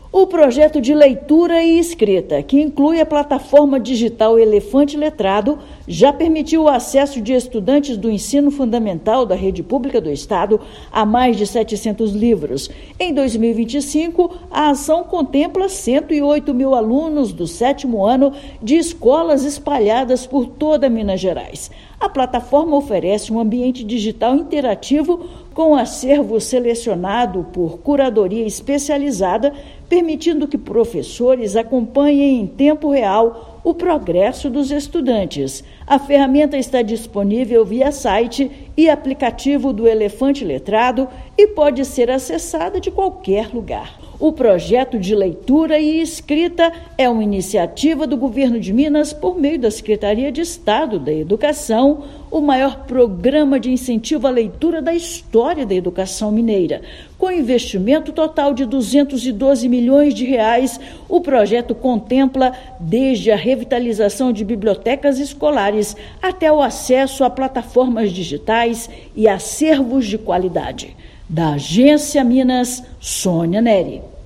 Plataforma faz parte do Projeto de Leitura e Escrita do Governo de Minas e já beneficia 108 mil alunos do 7º ano do ensino fundamental em 1.839 escolas da rede estadual. Ouça matéria de rádio.